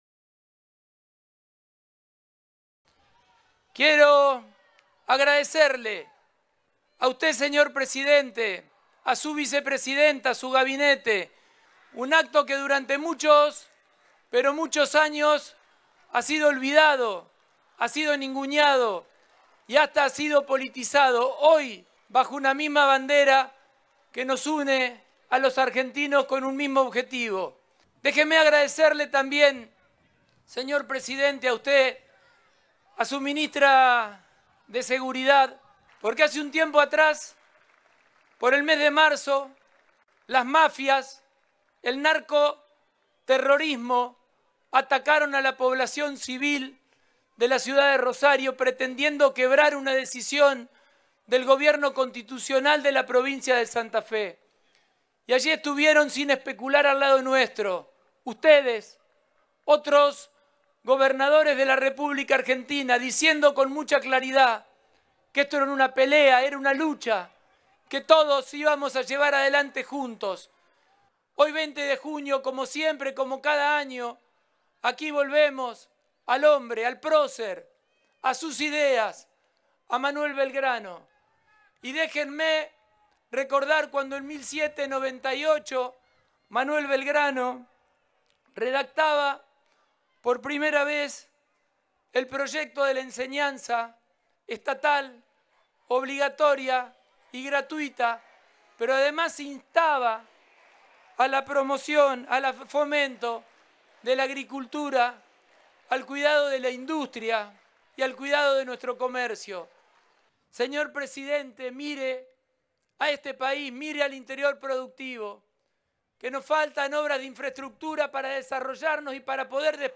Pullaro encabezó el acto central por el Día de la Bandera en la ciudad de Rosario